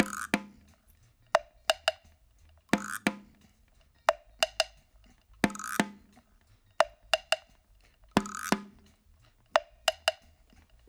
88-PERC5.wav